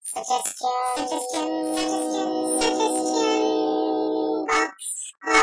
Suggestion Box Jingle Download The catchy Suggestion Box Jingle!